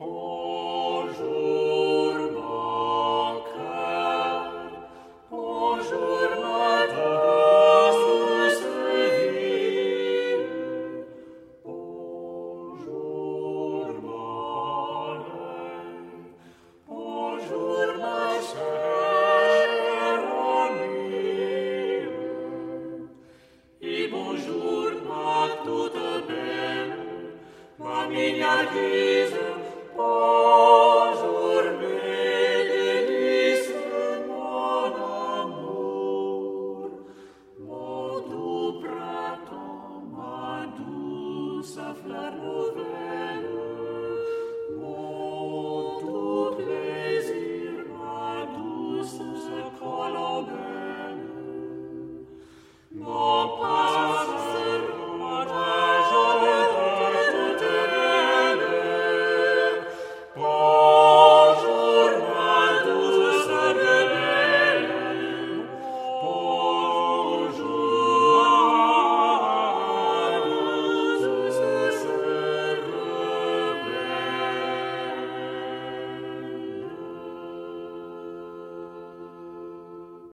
Le chant du moyen-âge que tu vas écouter est polyphonique.